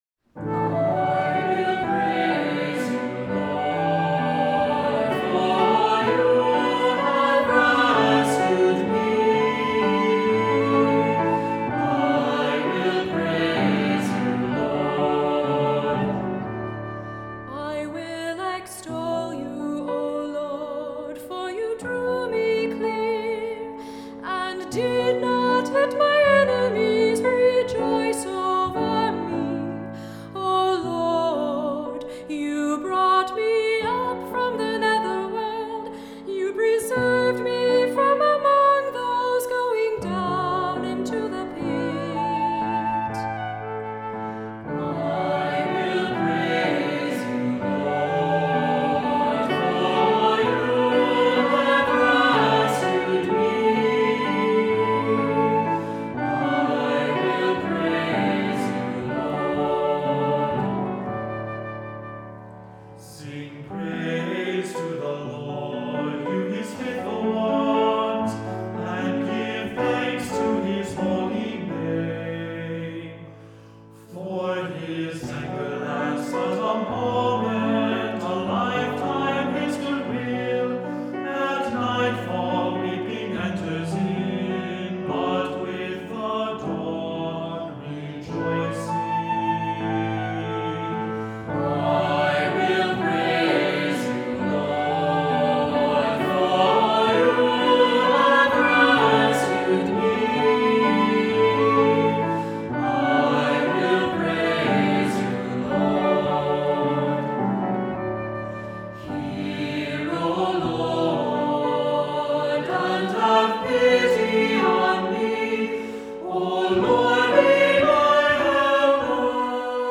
Voicing: Assembly, cantor, descant,Unison